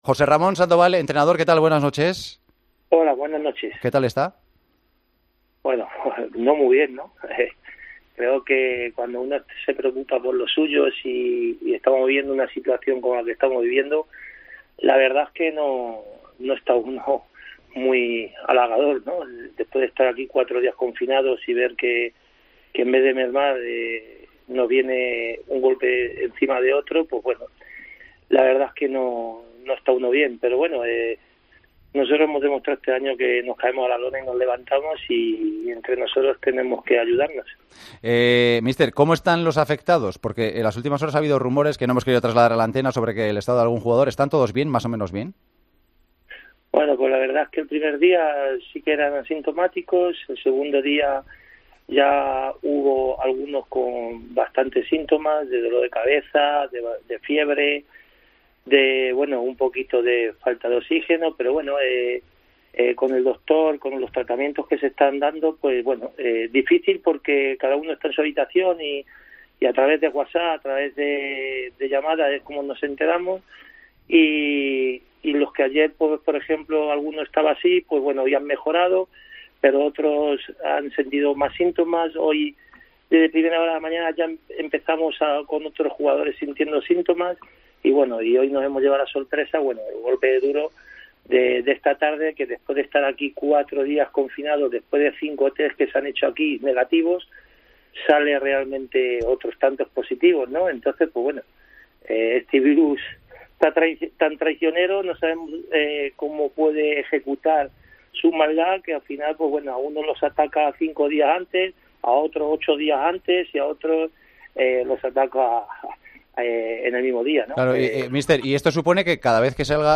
AUDIO - ENTREVISTA AL TÉCNICO DEL FUENLABRADA, JOSÉ RAMÓN SANDOVAL, EN EL PARTIDAZO DE COPE